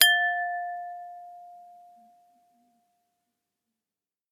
Glass Bowl 1
bell bowl chime ding glass ping pyrex ring sound effect free sound royalty free Sound Effects